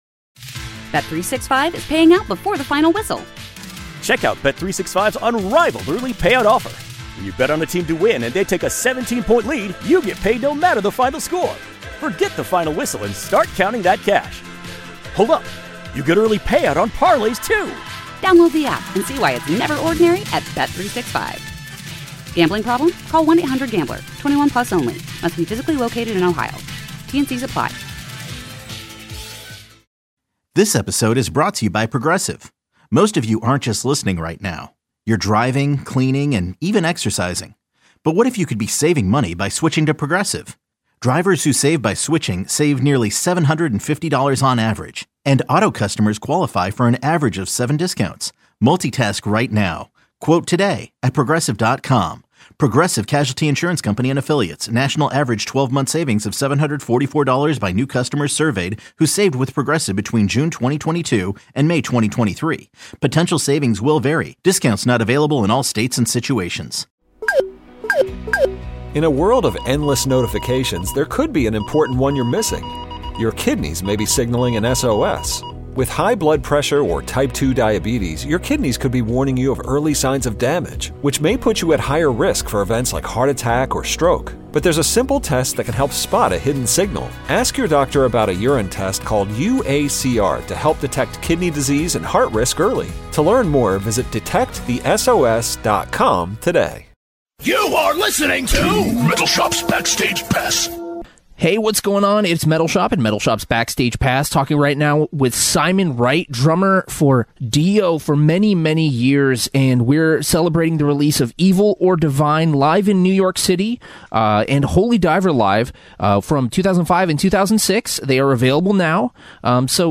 Beschreibung vor 4 Jahren Simon Wright is a legendary drummer in the world of rock n roll and metal who is most well-known for his years in AC/DC, UFO and of course DIO. I spoke with Simon about the release of the brand-new DIO live albums, what it’s like performing with a hologram of Ronnie James Dio, life during Covid, early influences and much more.